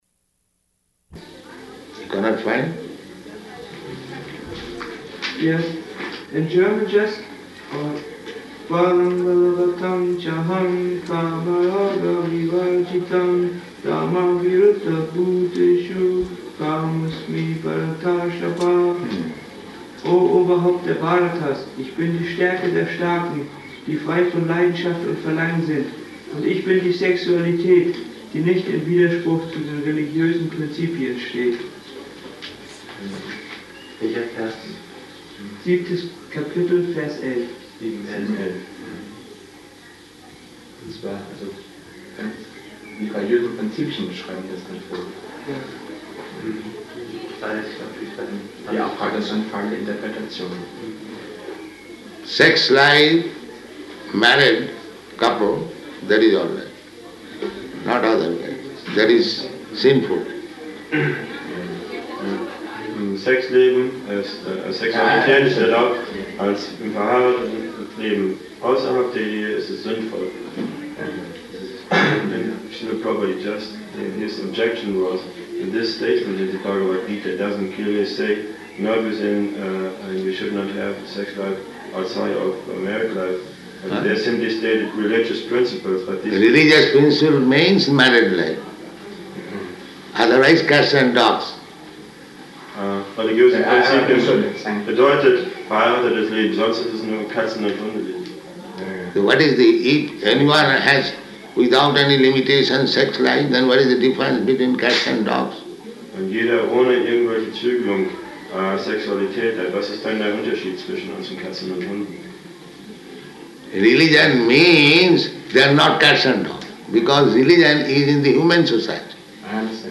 Room Conversation
Room Conversation --:-- --:-- Type: Conversation Dated: June 20th 1974 Location: Germany Audio file: 740620R1.GER.mp3 Prabhupāda: Cannot find?